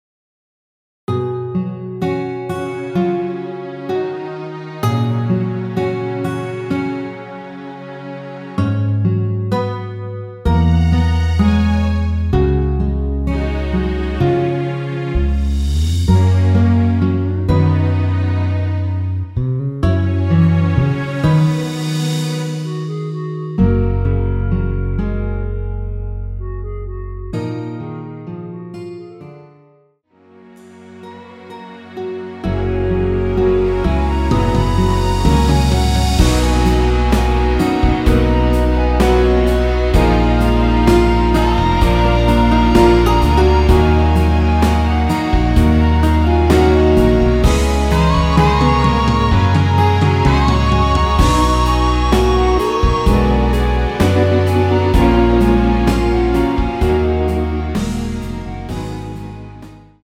원키에서(-2)내린 멜로디 포함된 MR입니다.
F#
앞부분30초, 뒷부분30초씩 편집해서 올려 드리고 있습니다.